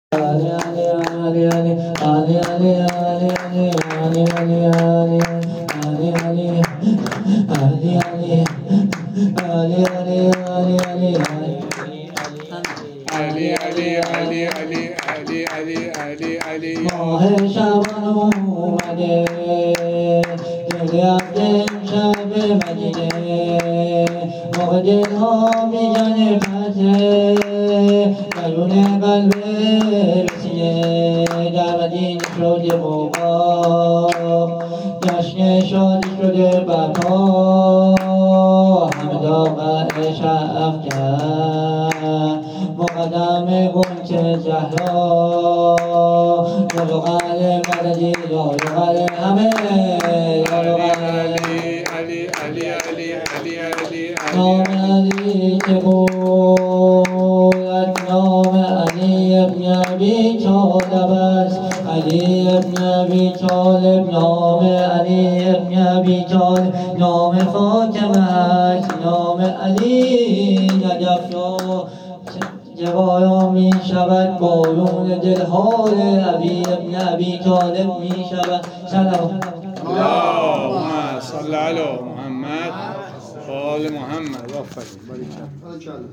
مداح اهل بیت
مولودی خوانی
هیئت ابافضل العباس امجدیه تهران